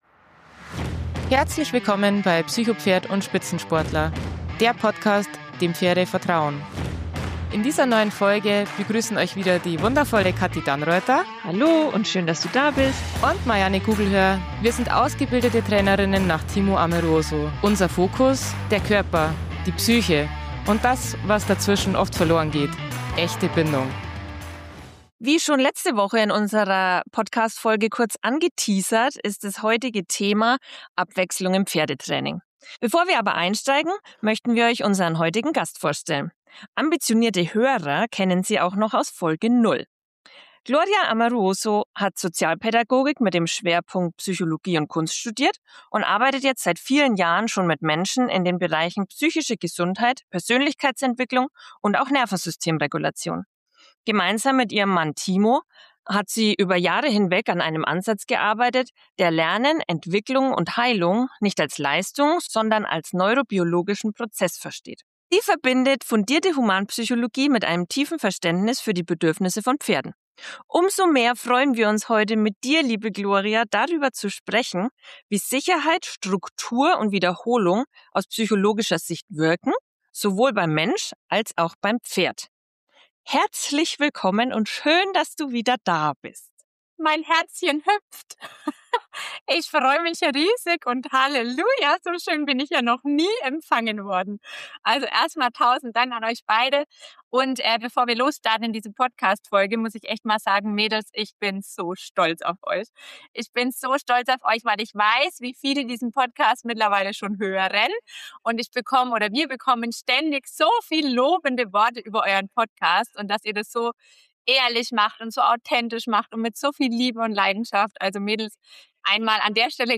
Ein ehrliches, tiefgehendes Gespräch über moderne Lernmuster, gesellschaftliche Veränderungen – und warum unser Bedürfnis nach Abwechslung nicht automatisch auf andere Systeme übertragbar ist.